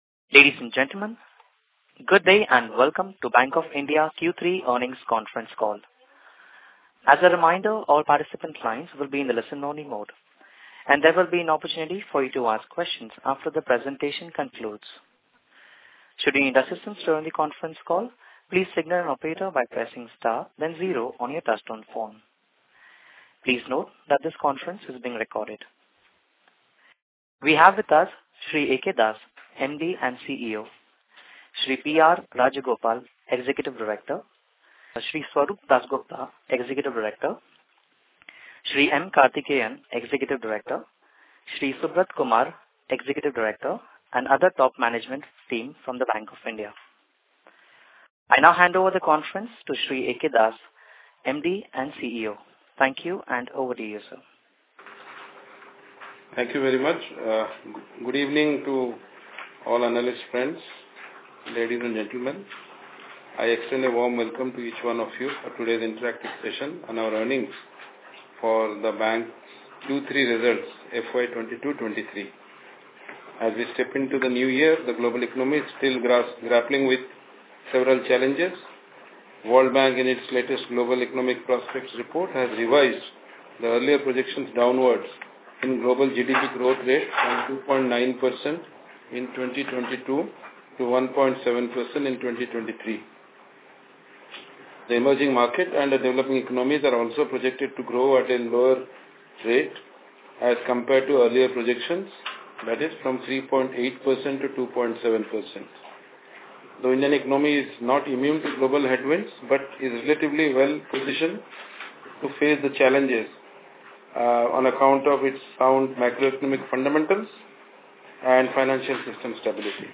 इन्व्हेस्टर्स कॉन्फरन्स कॉल रेकॉर्डिंग - BOI